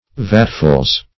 Vatfuls - definition of Vatfuls - synonyms, pronunciation, spelling from Free Dictionary Search Result for " vatfuls" : The Collaborative International Dictionary of English v.0.48: Vatful \Vat"ful\, n.; pl. Vatfuls .
vatfuls.mp3